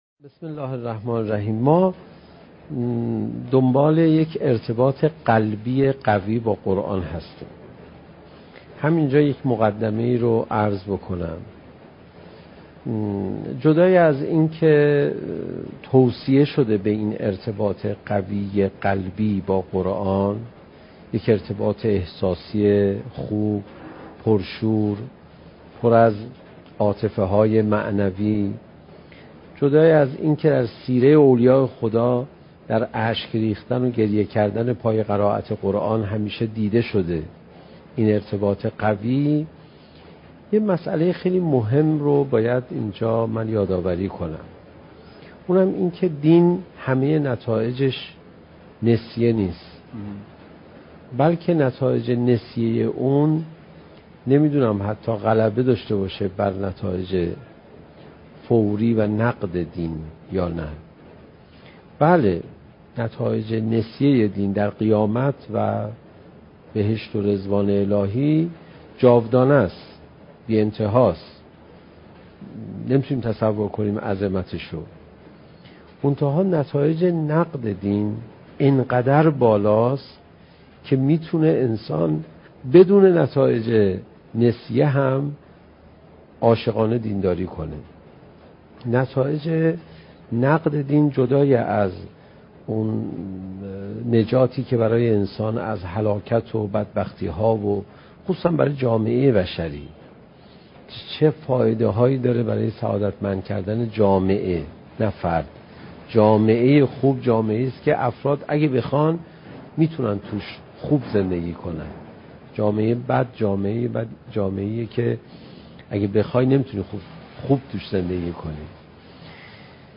سخنرانی حجت الاسلام علیرضا پناهیان با موضوع "چگونه بهتر قرآن بخوانیم؟"؛ جلسه پنجم: "حلاوت ایمان"